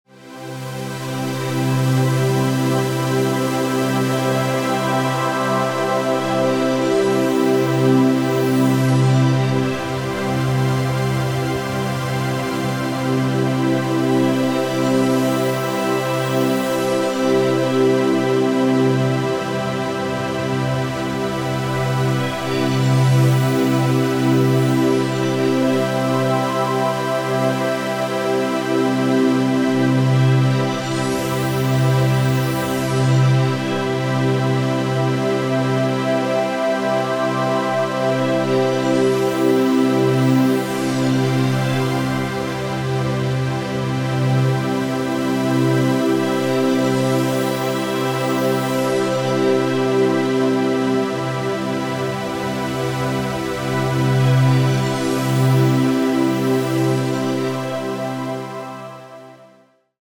Royalty free music elements: Pads
mf_SE-9052-solar_lunar_pad.mp3